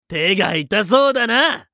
熱血系ボイス～戦闘ボイス～